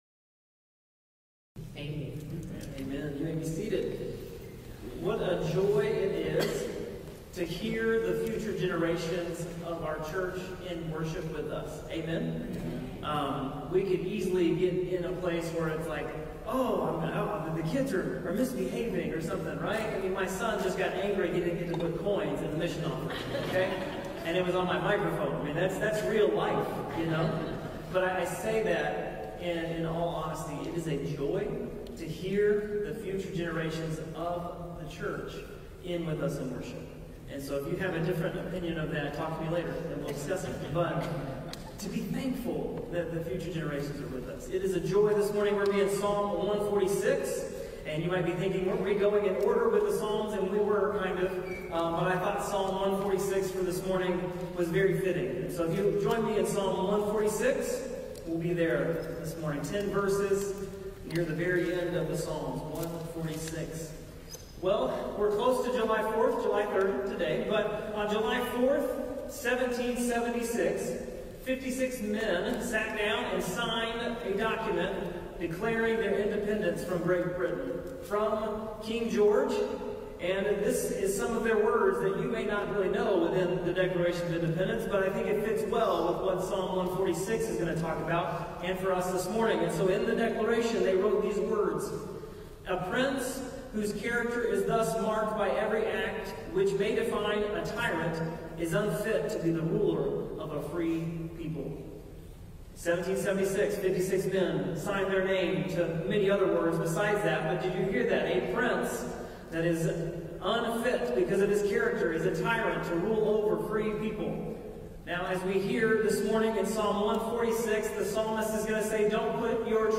Sermons | Longbranch Community Baptist Church